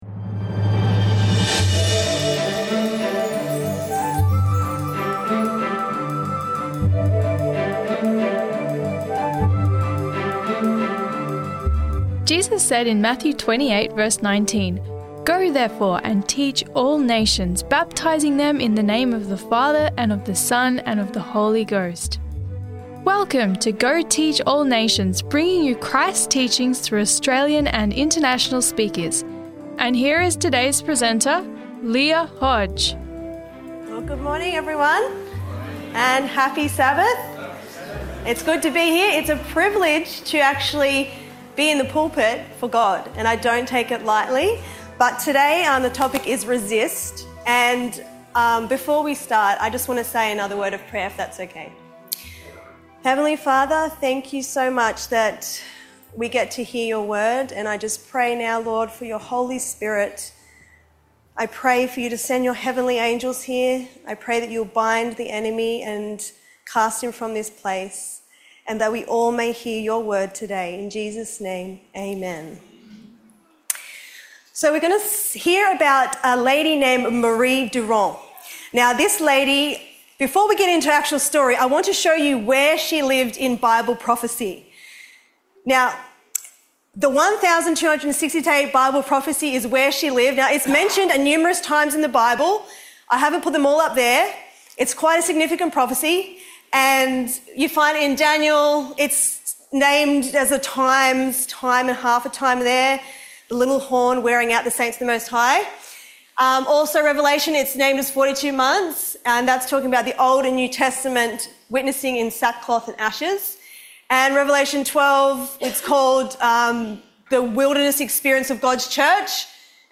Resisting the Mark of the Beast: Lessons from the Courageous Life of Marie Durand - Sermon Audio 2546